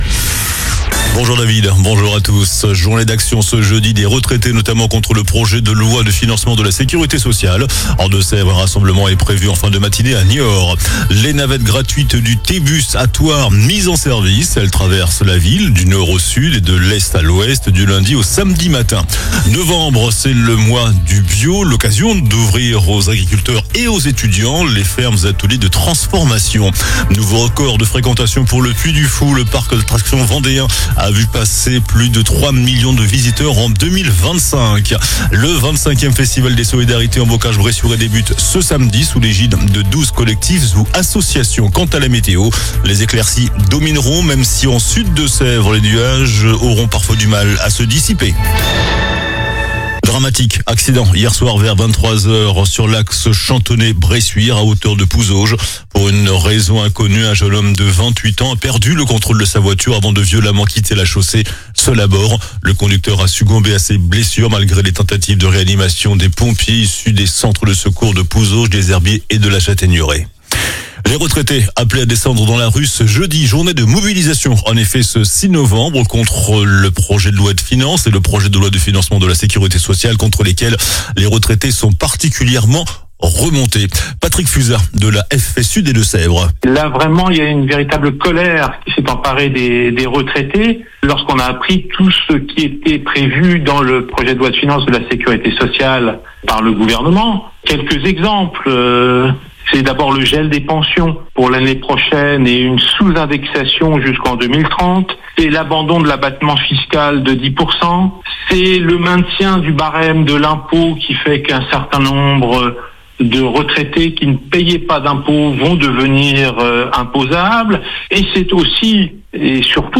JOURNAL DU MARDI 04 NOVEMBRE ( MIDI )